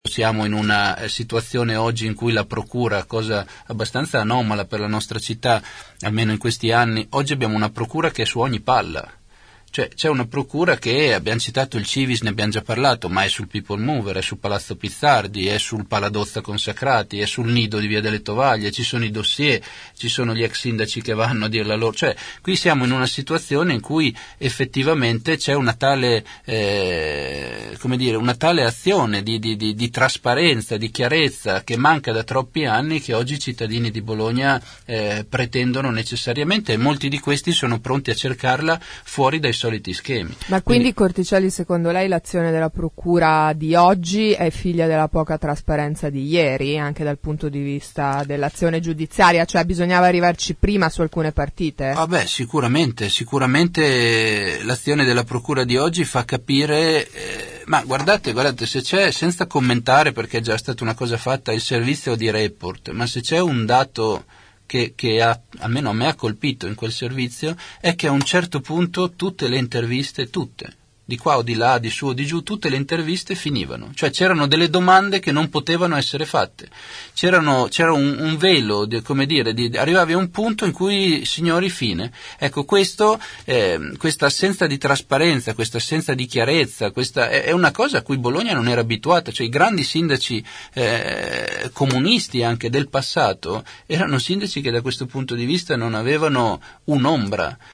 Il candidato civico ne ha parlato nei nostri studi rispondendo alle domande degli ascoltatori.